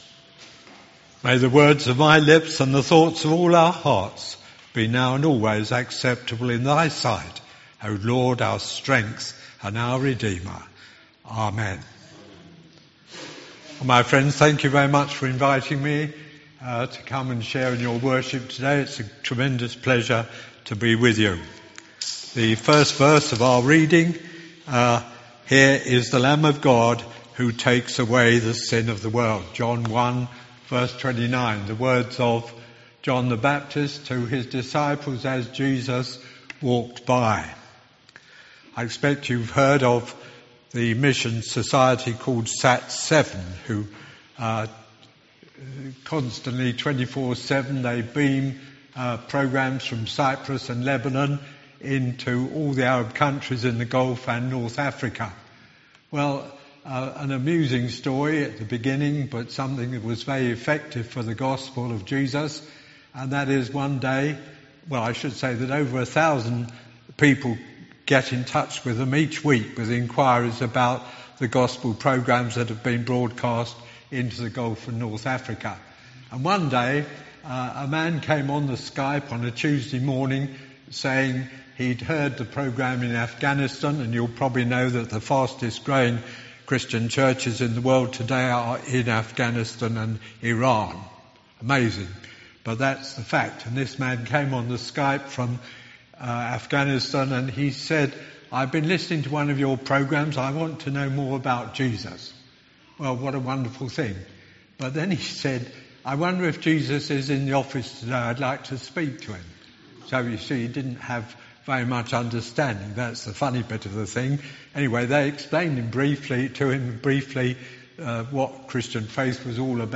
Sermon
An audio file of the service is now available to listen to.